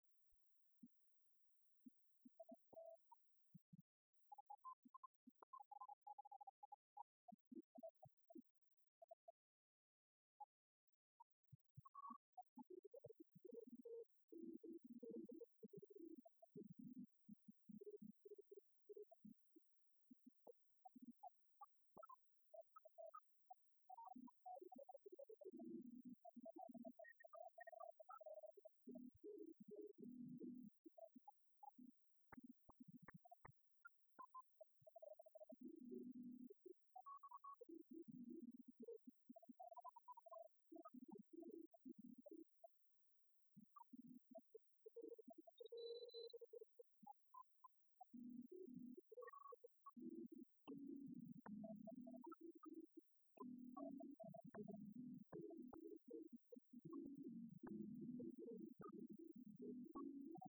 Oracle Arena
Lineage: Audio - AUD (ALD)